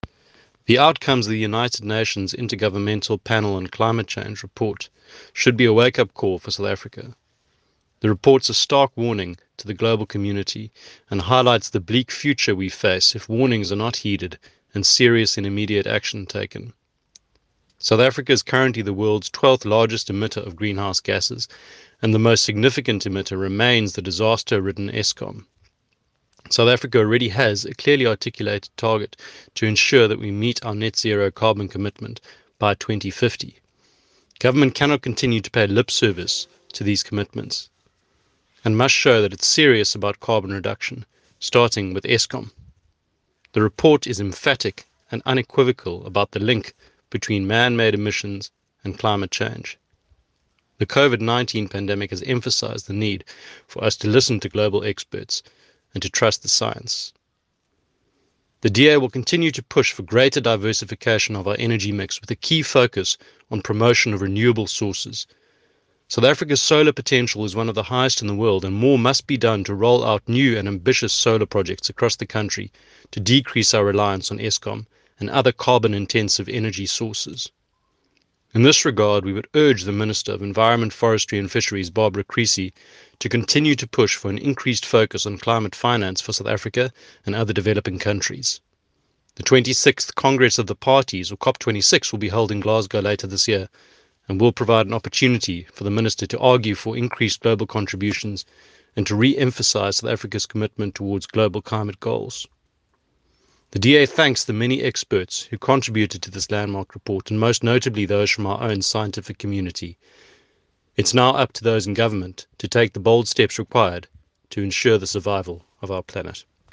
soundbite by Dave Bryant MP.